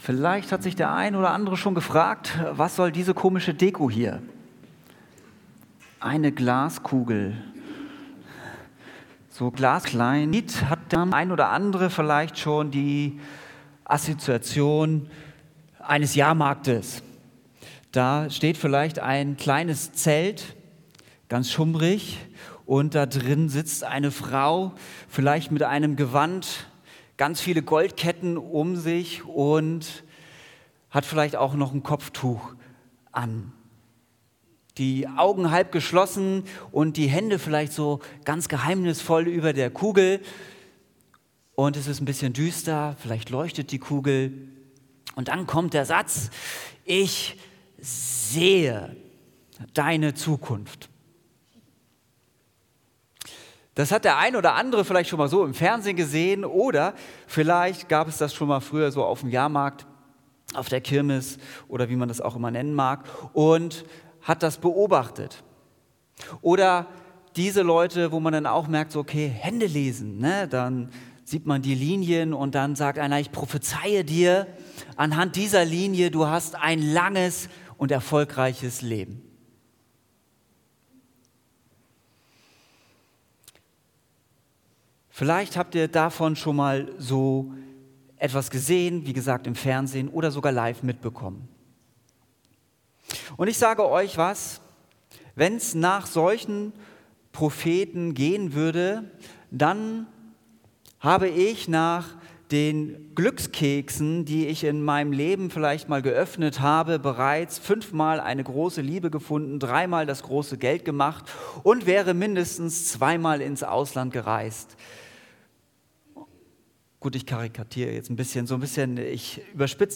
Willkommen bei der Evangelisch-Freikirchlichen Gemeinde Wiesbaden: Erleben Sie Gemeinschaft, Gottesdienste und vielfältige Angebote für jung und alt.